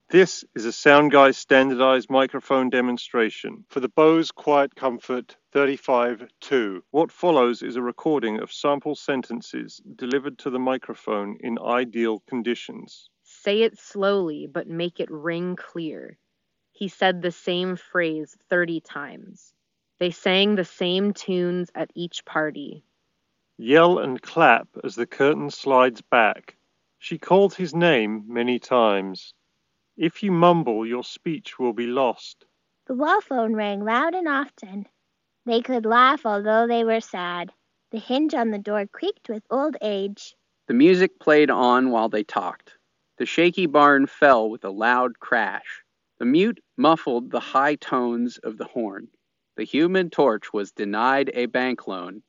Bose-QuietComfort-35-II_Ideal-microphone-sample.mp3